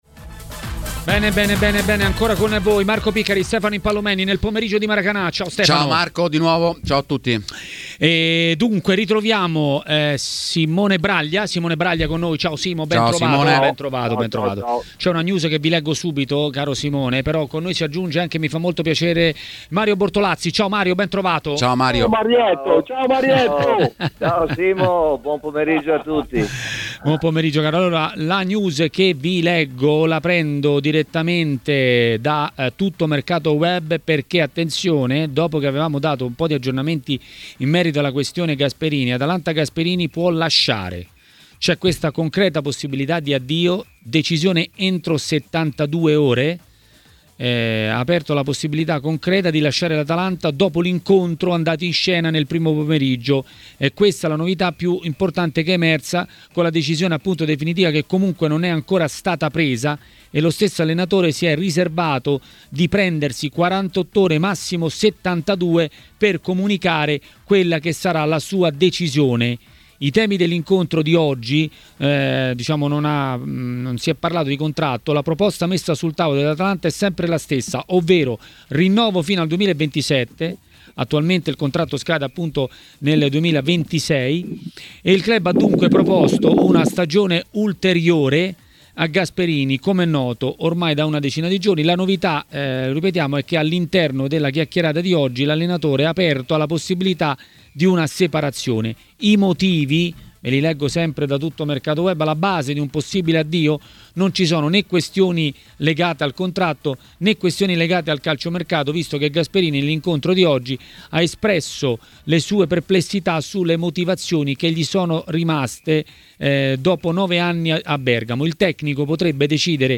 L'ex calciatore e tecnico Mario Bortolazzi è intervenuto a TMW Radio, durante Maracanà.